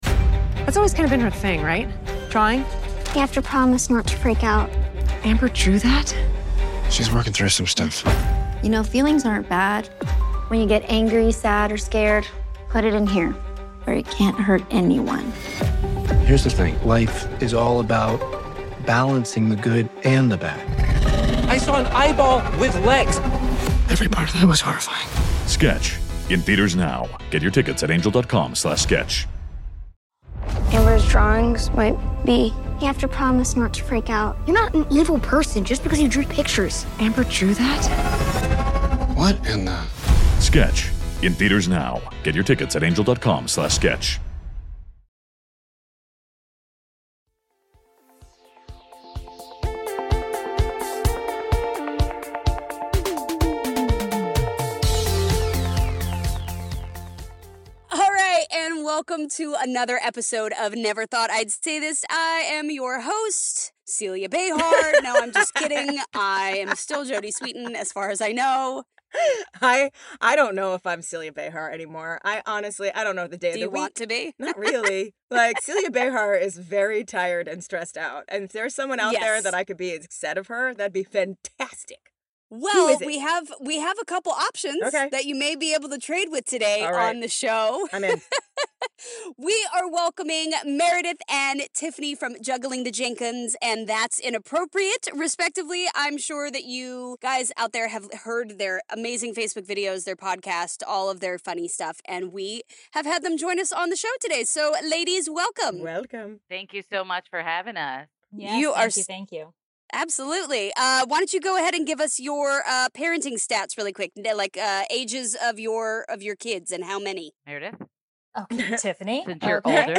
From their respective closets/garages/wells, the ladies discuss everything from conspiracy theories, butts (yes, again), the online parenting community, and how to charge your kids for snacks during quarantine